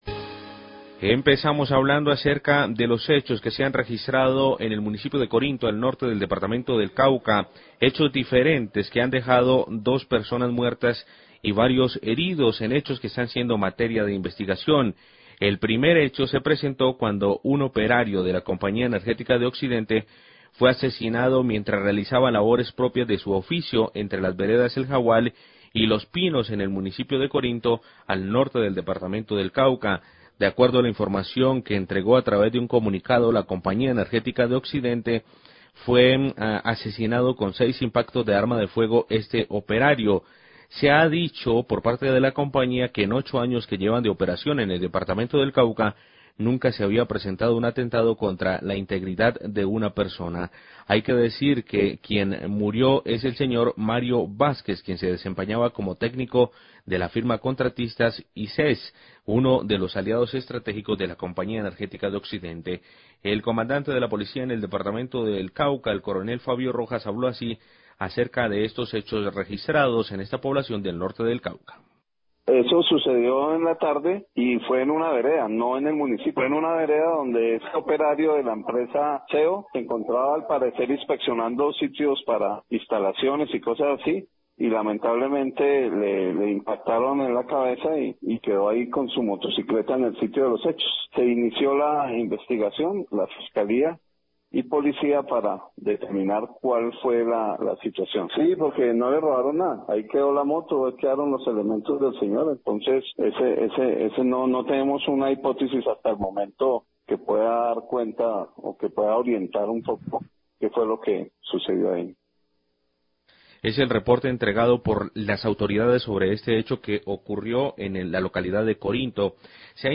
Radio
La Compañía energética emitió un comunicado de prensa por los hechos presentados. Declaraciones del Coronel Fabio Rojas, Comandante de la Policía del Cauca.